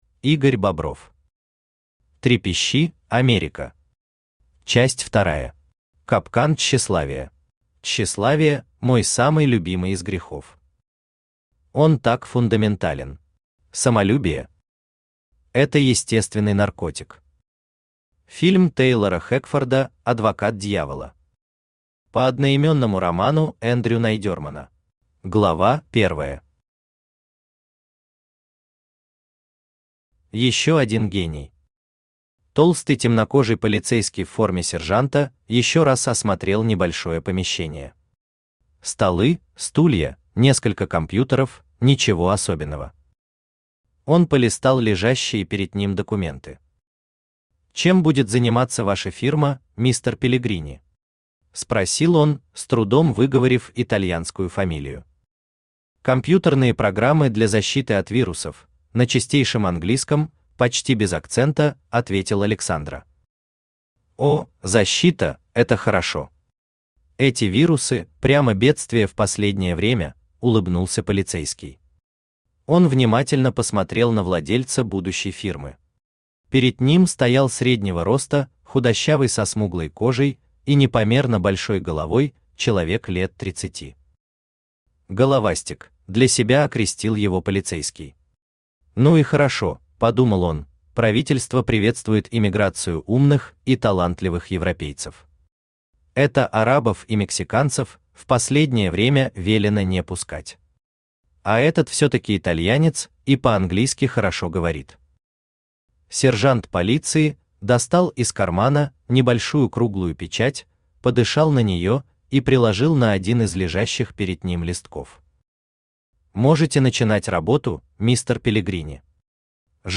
Аудиокнига Трепещи, Америка! Часть вторая. Капкан тщеславия | Библиотека аудиокниг
Капкан тщеславия Автор Игорь Ильич Бобров Читает аудиокнигу Авточтец ЛитРес.